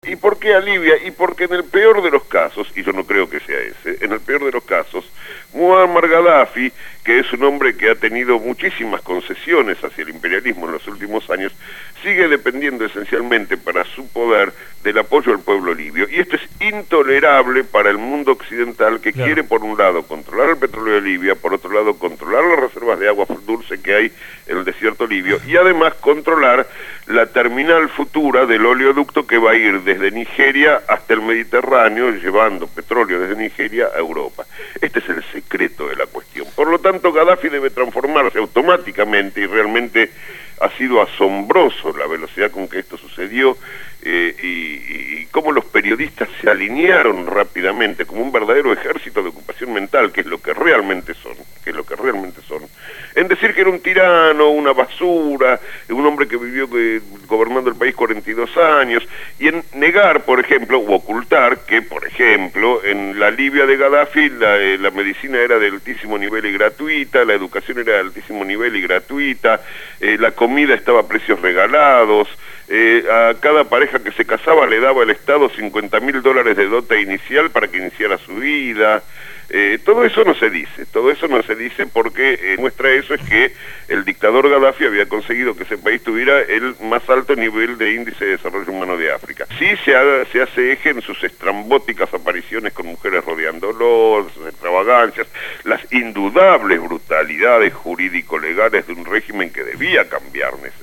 analista internacional, analiza los sucesos en Libia en el programa «Desde el barrio» (Lunes a viernes de 9 a 12 horas) por Radio Gráfica.